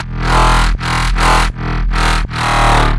描述：用Massive制作的。它是160 bpm。这个声音适合于dubstep和drumstep或dnb。
标签： 160 bpm Dubstep Loops Bass Wobble Loops 1.01 MB wav Key : E
声道立体声